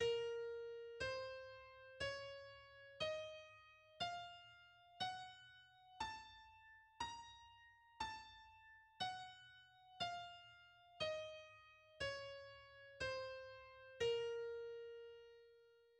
A-sharp minor
The A-sharp harmonic minor and melodic minor scales are: